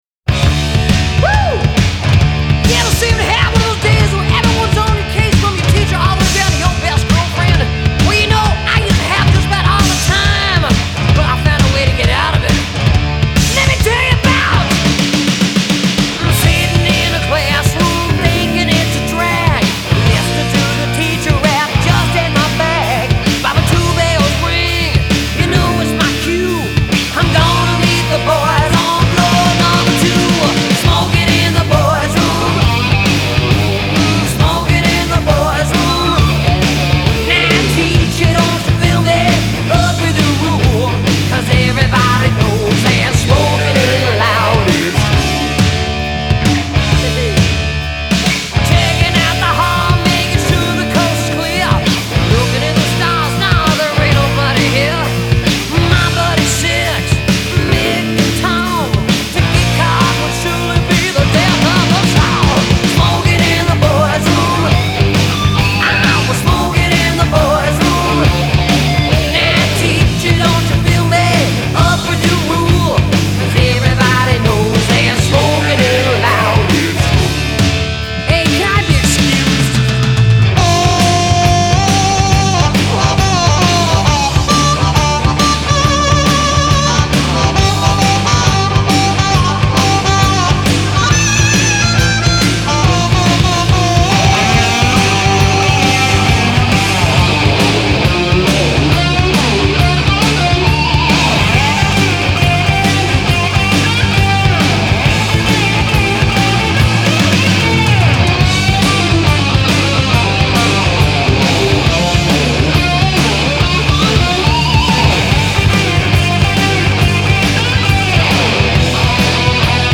Genre : Rock, Metal